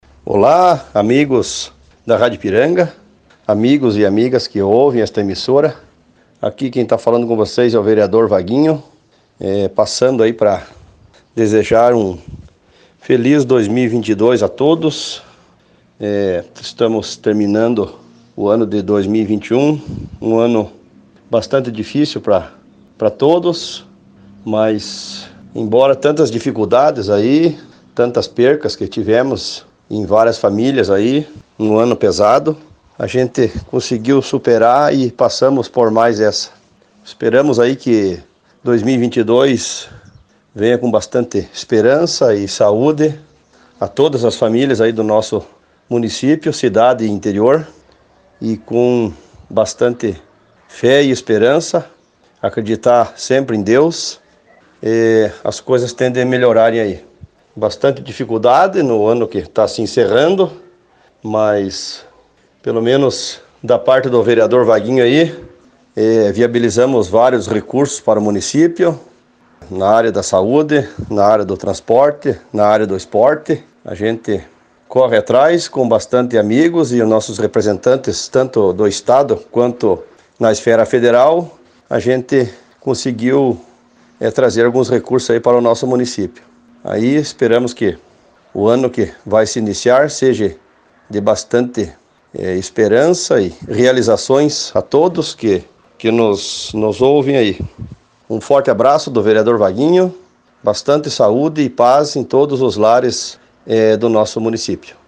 Ouça a fala do vereador Vaguinho